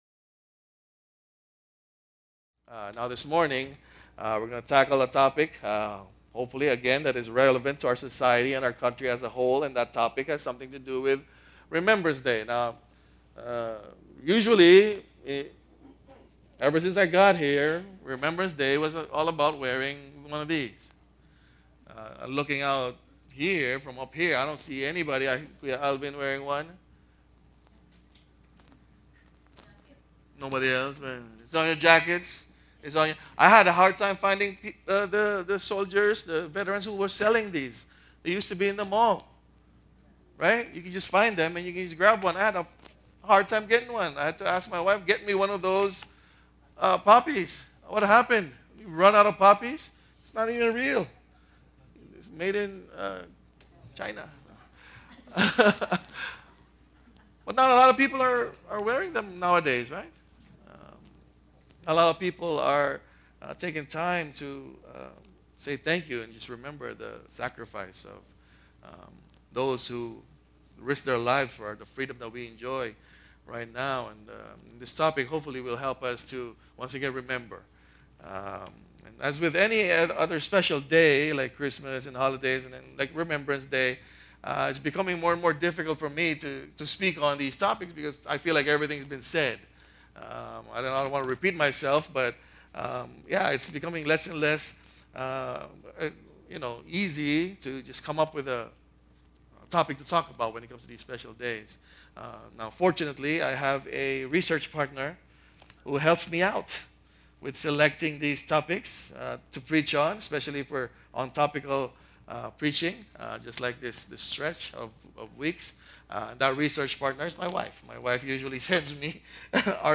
Quotes in the sermon are from the Christian Today article, Remembrance Day: What The Poppy Really Means, And How Christians Should Respond by Mark Woods, Nov. 11, 2016.